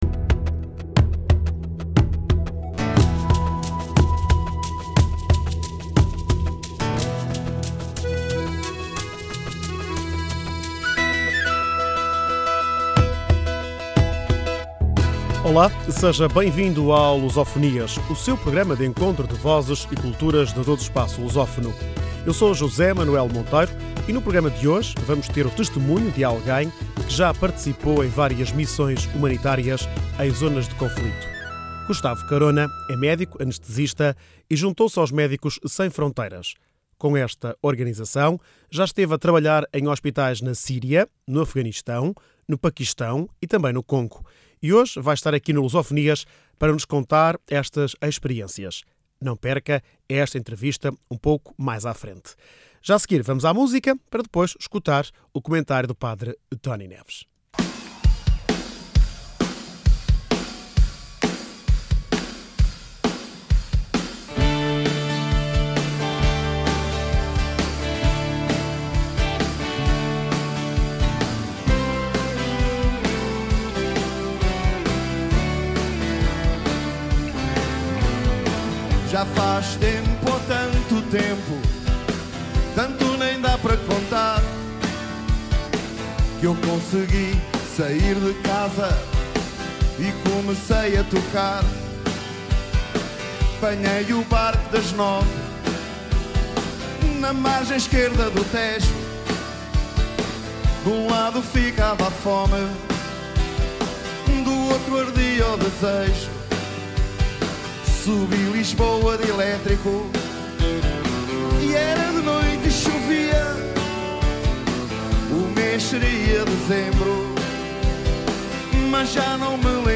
Este Luso Fonias conta com o testemunho de alguém que já participou em várias missões humanitárias em zonas de conflito.